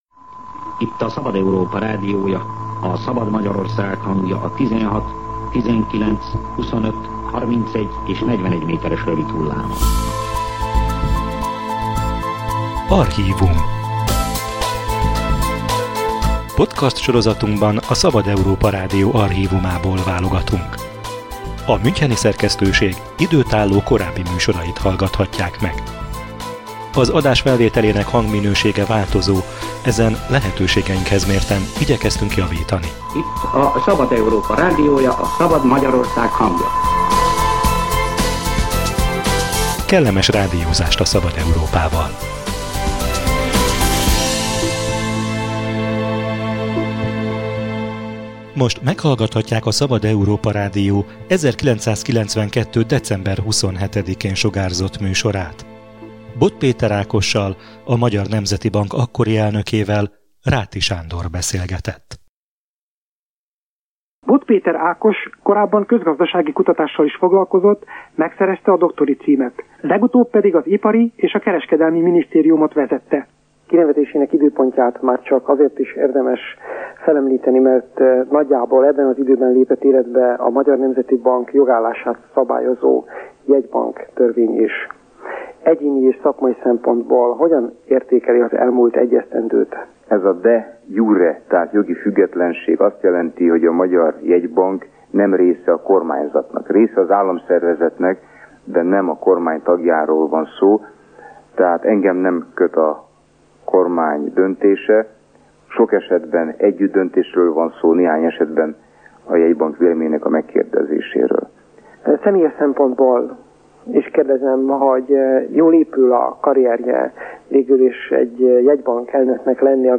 Az államszervezet része, de nem a kormányé - archív műsor a jegybanki függetlenségről
Ebben az időszakban készült a Szabad Európa Rádió interjúja Bod Péter Ákossal, a jegybank akkori elnökével. Szóba kerül, miben különbözik munkája és szakértelme egy politikusétól.